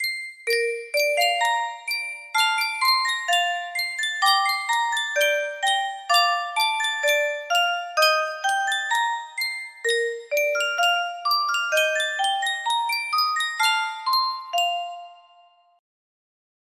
Yunsheng Music Box - Brahms String Sextext No. 1 6005 music box melody
Full range 60